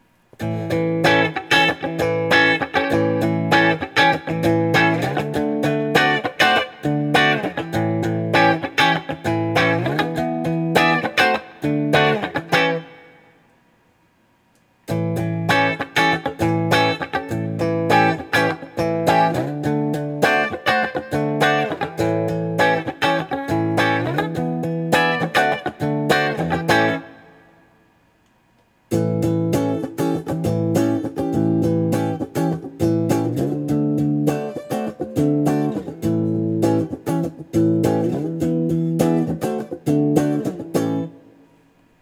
7th Chords
For these recordings I used my normal Axe-FX Ultra setup through the QSC K12 speaker recorded into my trusty Olympus LS-10.
For this guitar I recorded each selection with the tone set to 10, then 7, then 0.